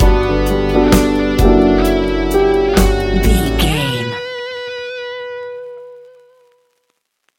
Ionian/Major
B♭
laid back
Lounge
sparse
new age
chilled electronica
ambient
atmospheric
instrumentals